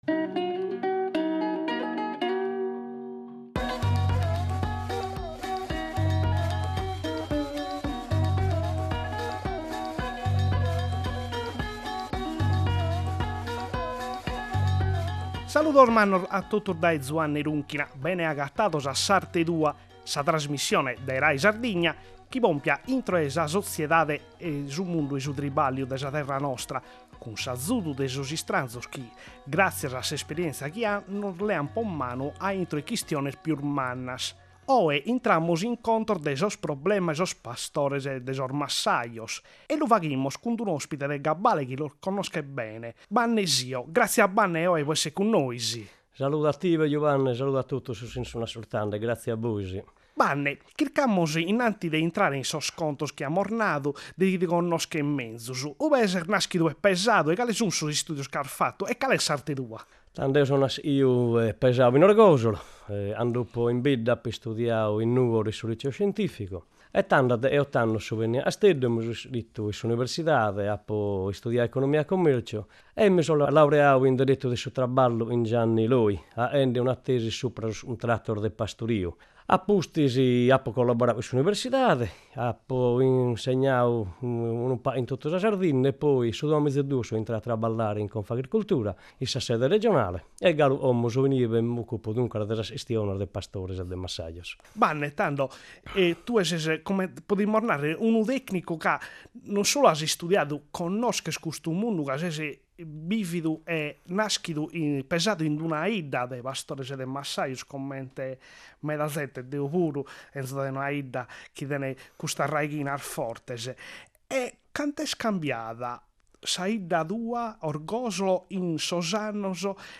S’ARTE TUA – Intervista